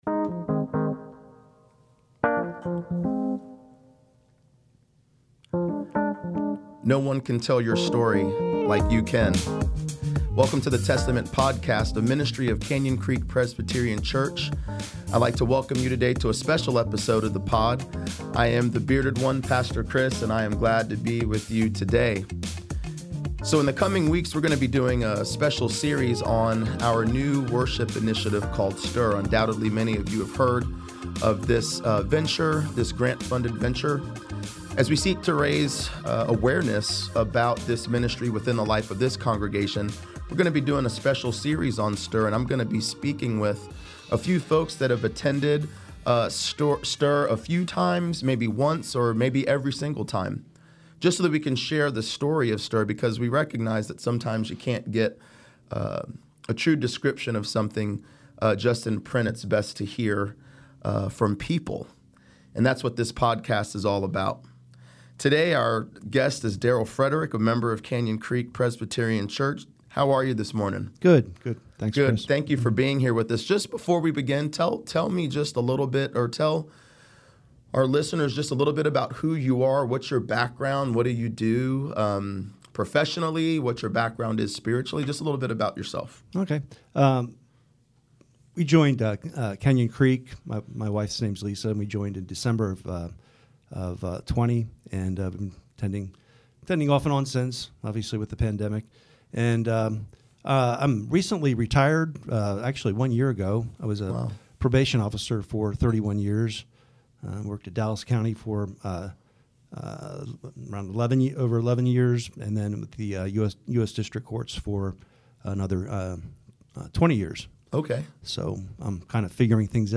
STIR Worship Interview One